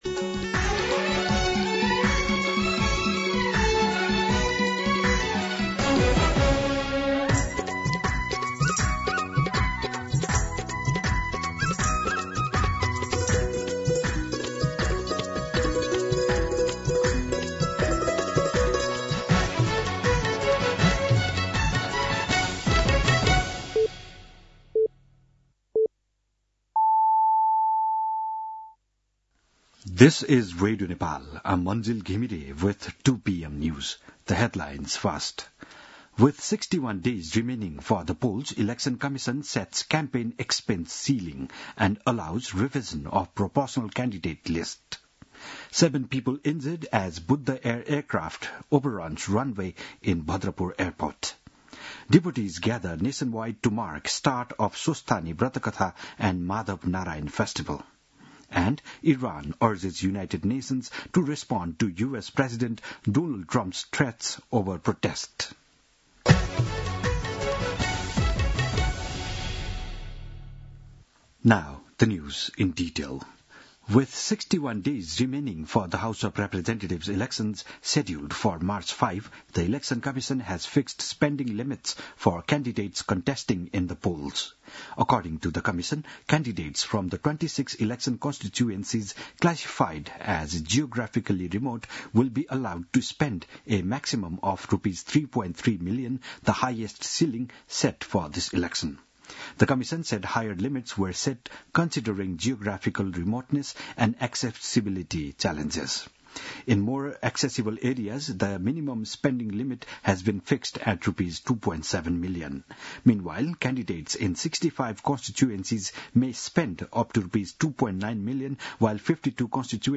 दिउँसो २ बजेको अङ्ग्रेजी समाचार : १९ पुष , २०८२